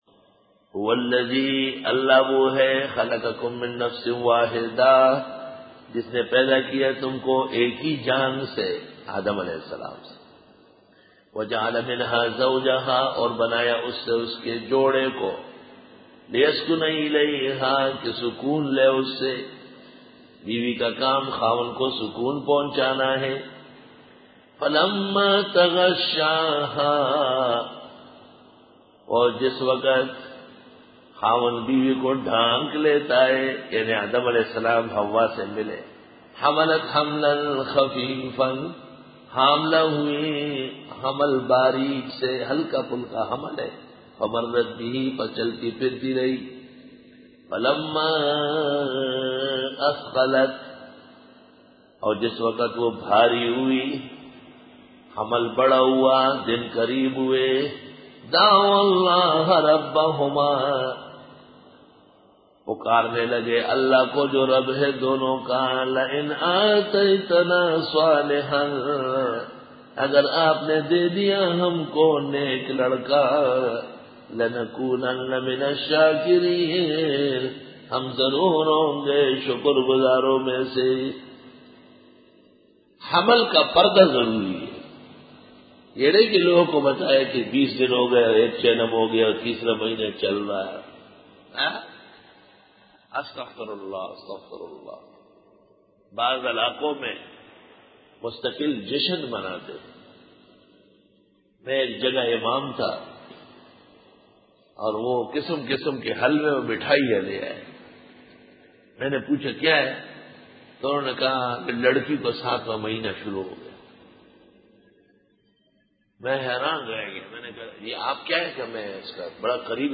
Dora-e-Tafseer 2005